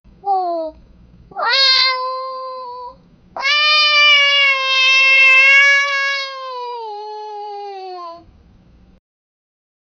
Free AI Sound Effect Generator
crayin-kid-sound-zy37w2wx.wav